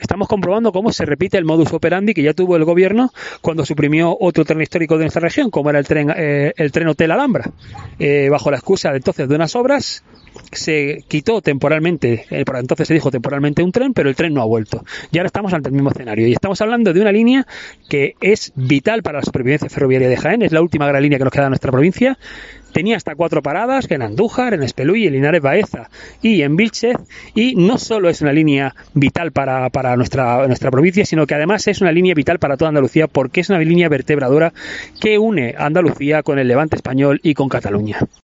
Ildefonso Ruiz portavoz de Ciudadanos en la Diputación Provincial